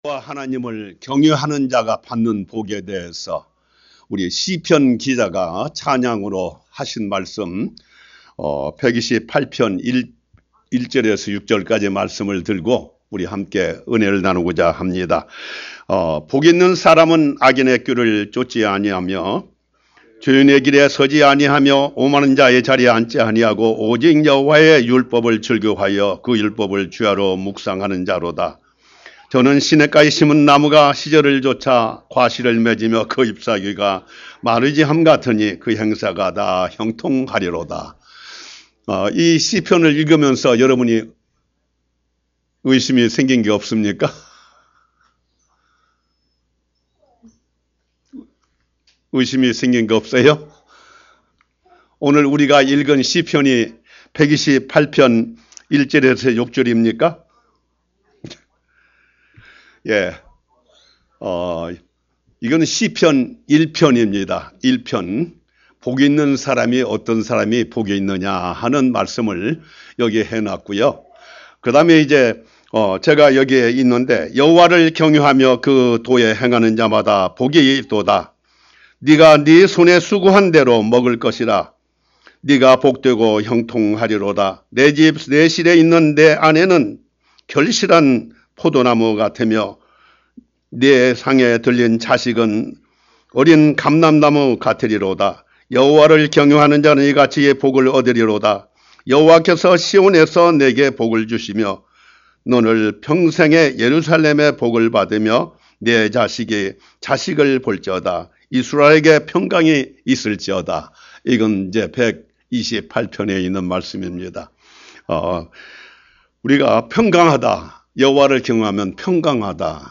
Sermon - 여호와 하나님을 경외하는 자가 받는 복 The blessings to those that fear God.